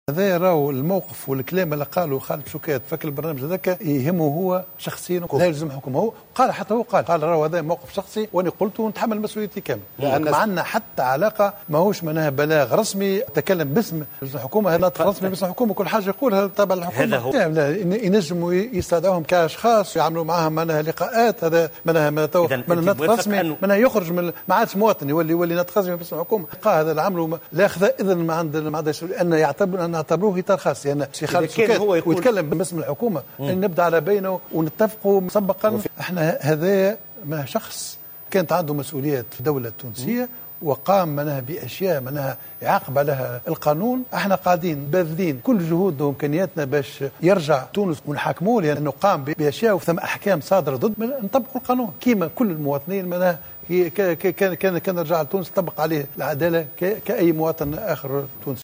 قال رئيس الحكومة، الحبيب الصيد، في حوار تلفزي أجرته معه القناة الوطنية الاولى وقناة فرنسا 24مساء الخميس، إن تصريحات الناطق الرسمي باسم الحكومة، خالد شوكات والتي تمنى فيها عودة الرئيس الأسبق زين العابدين بن علي، لا تلزم إلا شخصه، ولا تعبر عن موقف الحكومة.